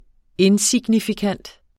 Udtale [ ˈen- ]